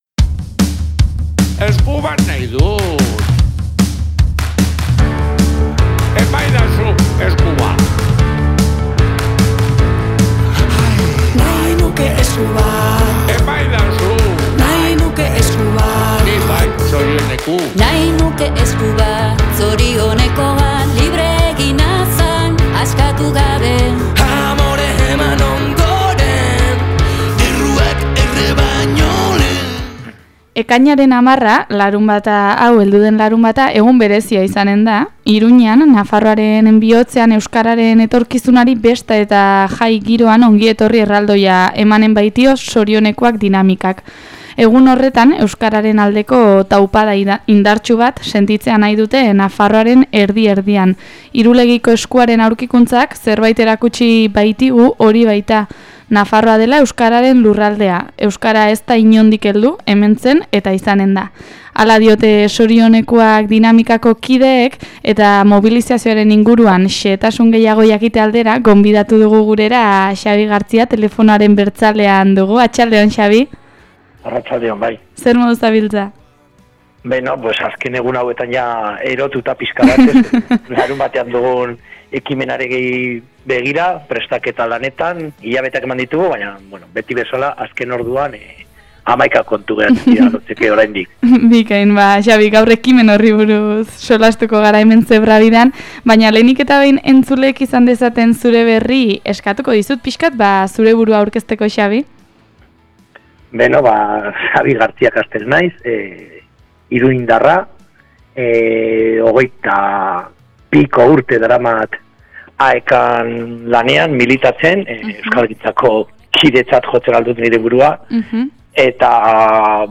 Eguneko elkarrizketa